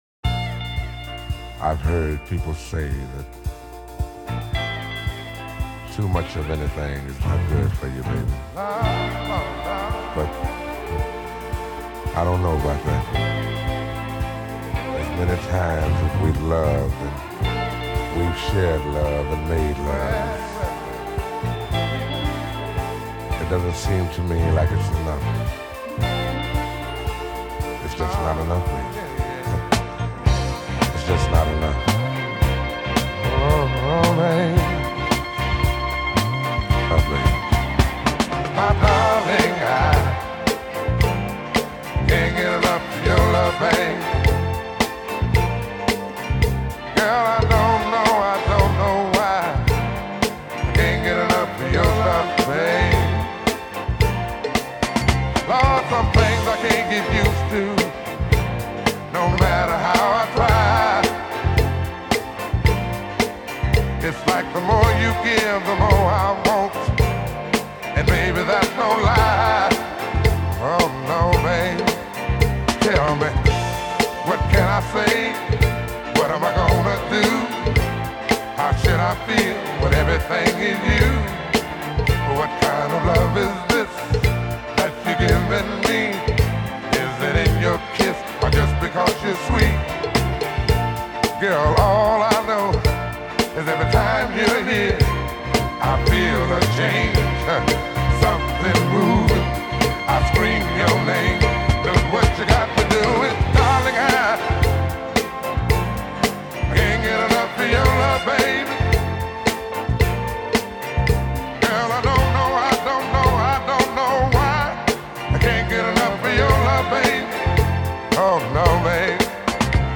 un barítono atronador que un buen día